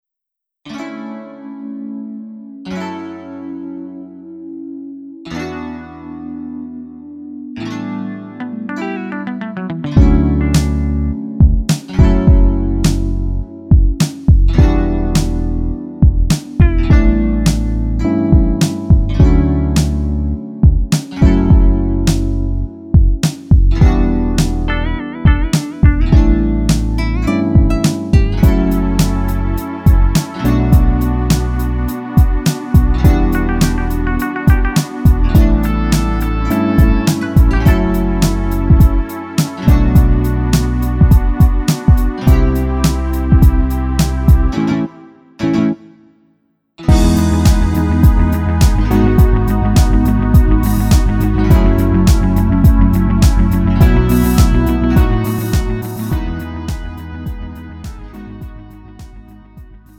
음정 원키 3:41
장르 구분 Lite MR